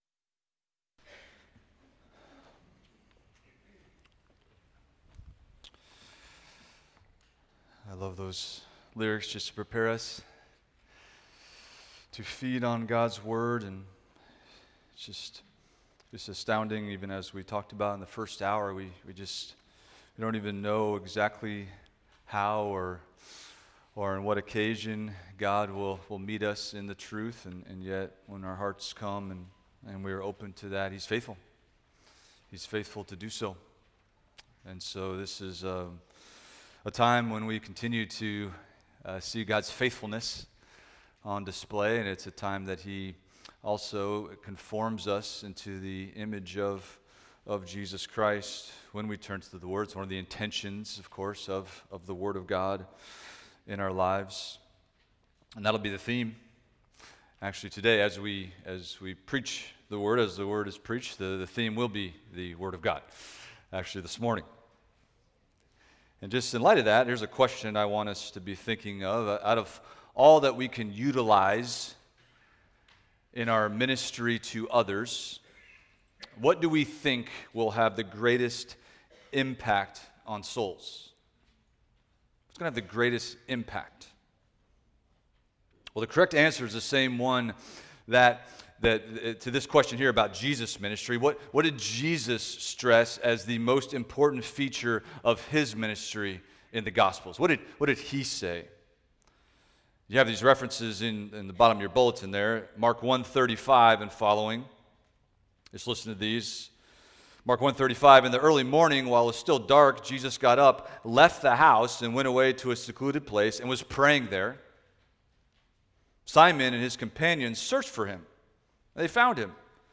Expository Preaching through the Book of Acts